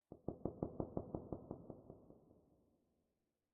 Minecraft Version Minecraft Version 1.21.5 Latest Release | Latest Snapshot 1.21.5 / assets / minecraft / sounds / ambient / underwater / additions / crackles2.ogg Compare With Compare With Latest Release | Latest Snapshot
crackles2.ogg